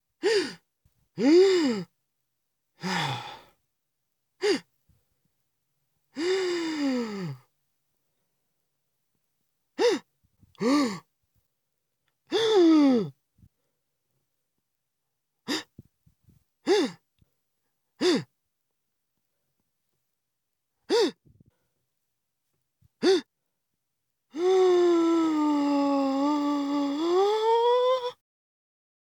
Gasping sounds
Category 🤣 Funny
alarm breath dumbfounded funny gasp inhale OWI shock sound effect free sound royalty free Funny